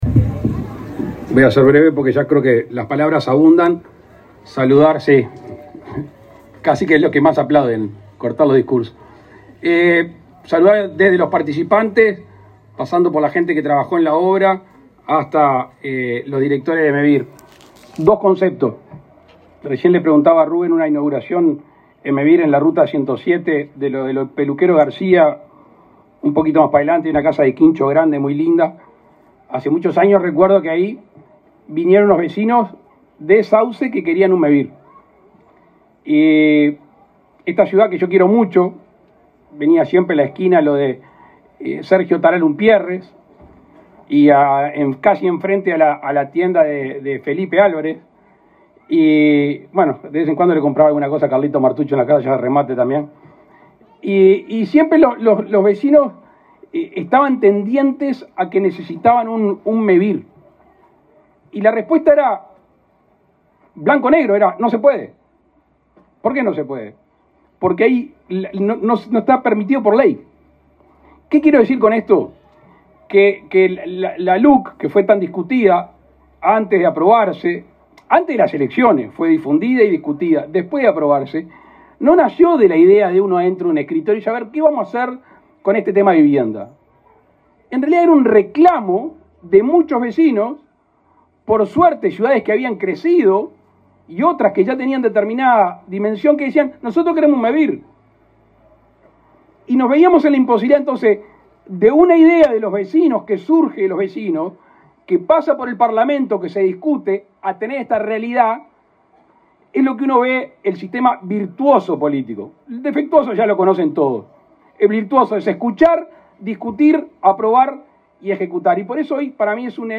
Palabras del presidente Luis Lacalle Pou
El presidente Luis Lacalle Pou encabezó la inauguración de 89 viviendas de Mevir en la localidad de Sauce, Canelones.